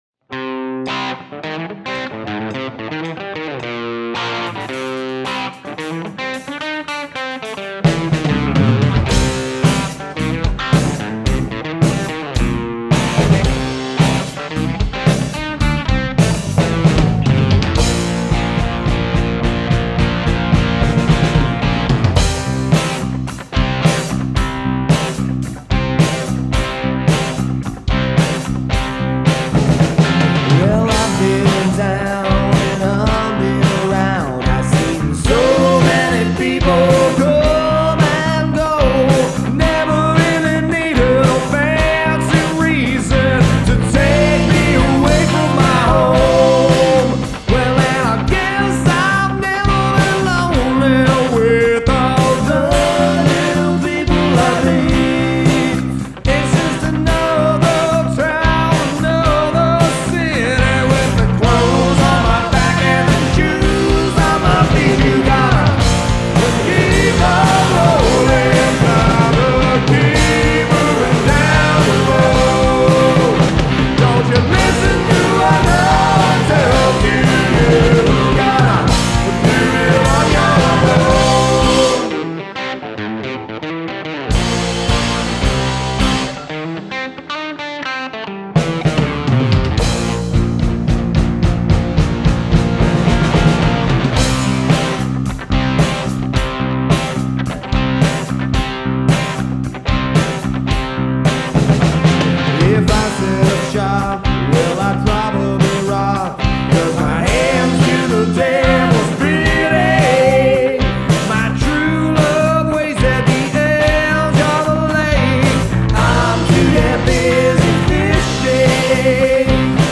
Rolling Down The Road (Classic Rock)
I tried our some new vocal harmonies and phrasings that were quite out of my element.
This little diddy continues the trend of 2025 where I am seaming to focus on riff based guitar lines for verses and more deliberate Chord and vocal harmony based choruses. FYSA i play and record all the instruments and vocals.
Music / 70s